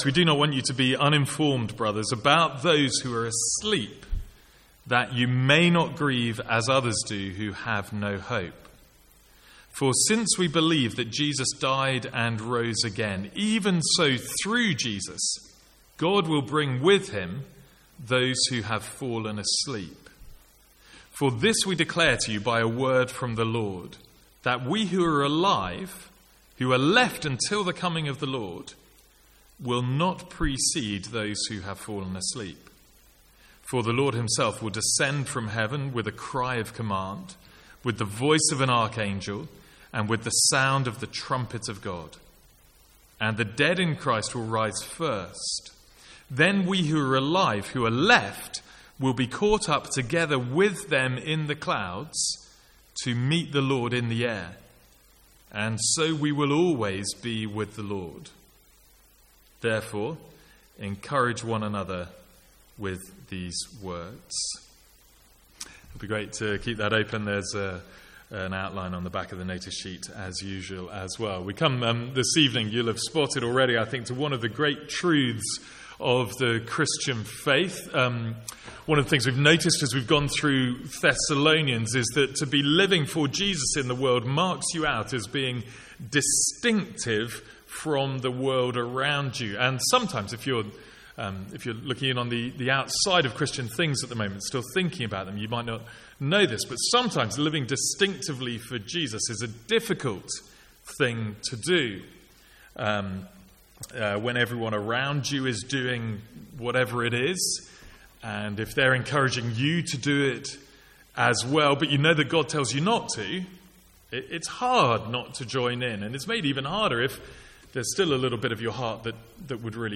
From the Sunday evening series in 1 Thessalonians.